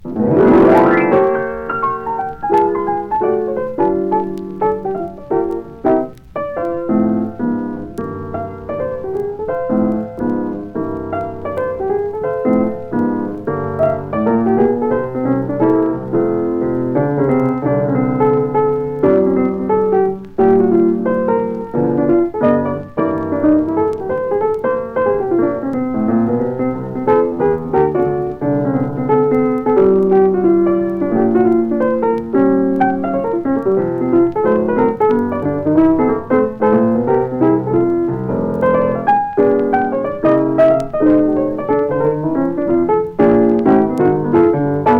Jazz, Blues　USA　12inchレコード　33rpm　Mono